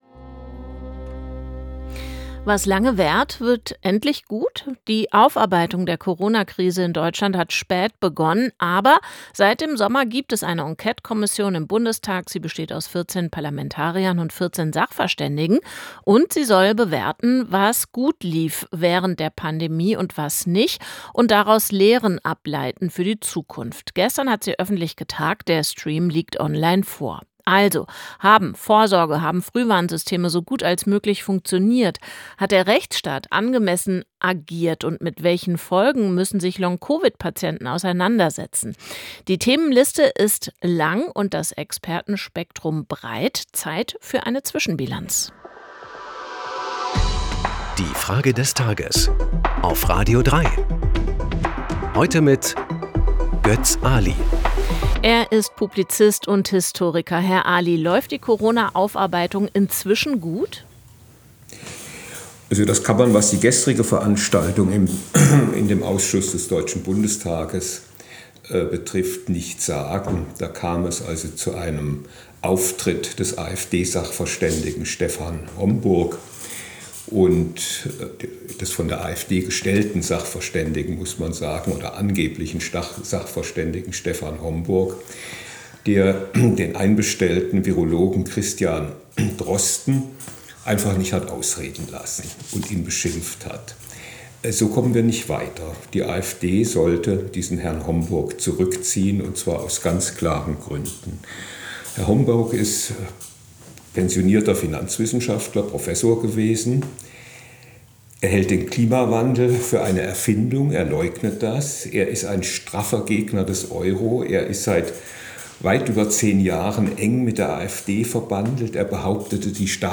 Es antwortet der Politikwissenschaftler und Historiker